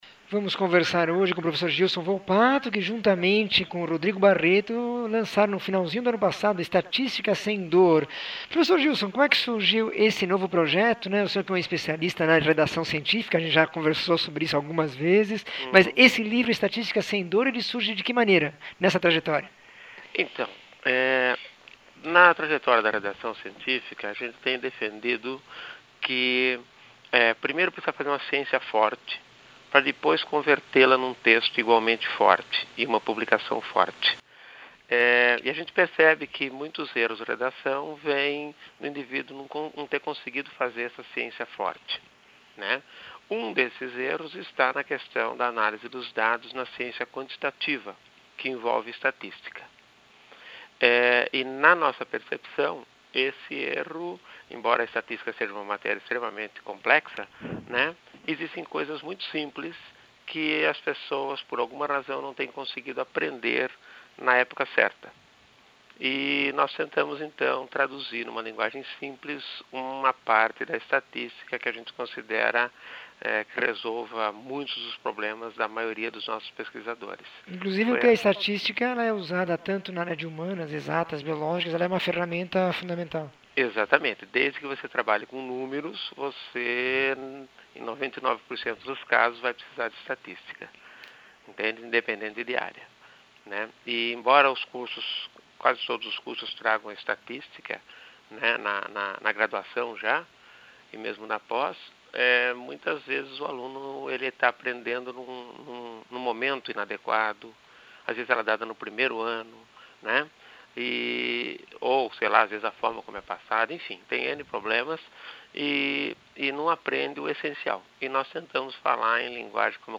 Entrevista com um dos autores do livro Estatística sem dor.